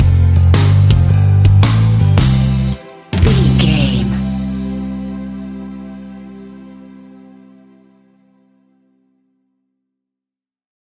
Ionian/Major
melancholic
happy
energetic
smooth
uplifting
electric guitar
bass guitar
drums
pop rock
indie pop
instrumentals
organ